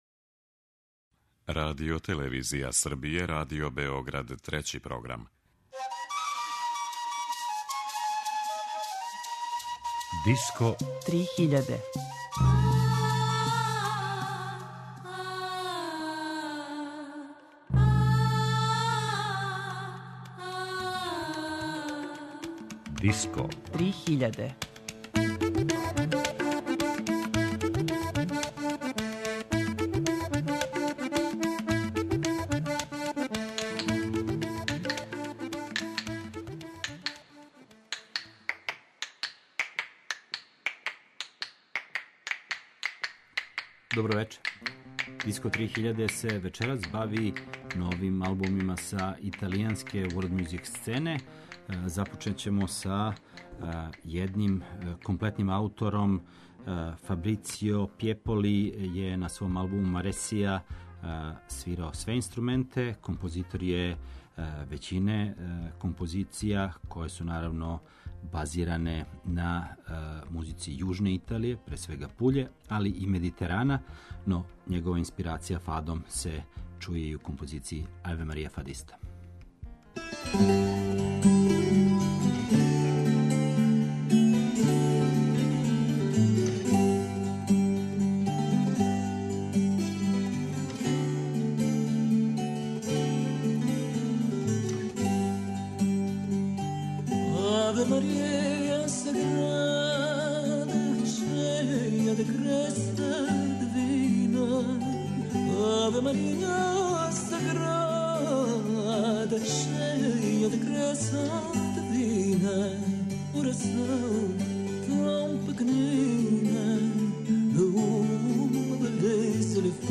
Једна од европских world music сцена која не престаје да одушеваљава квалитетом и оригиналношћу је италијанска.